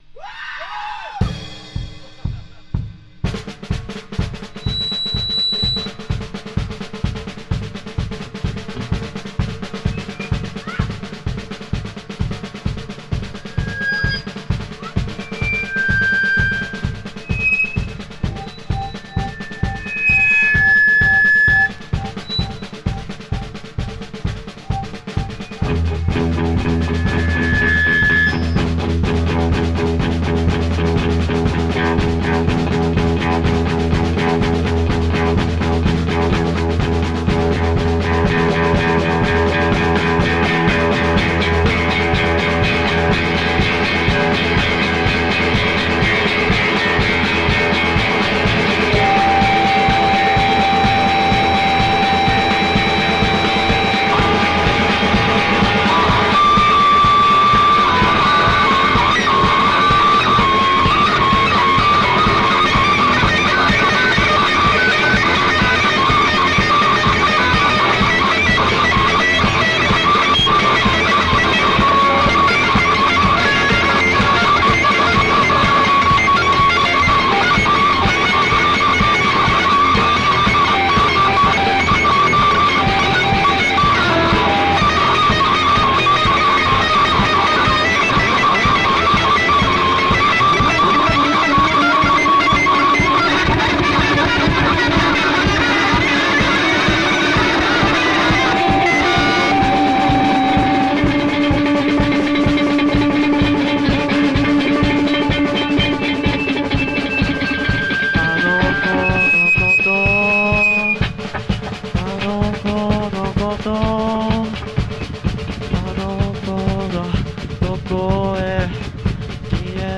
ロックンロール、ガレージ、ノーウェイヴ、サイケデリックが高次元でブレンドされたサウンドは理屈抜きの格好良さ！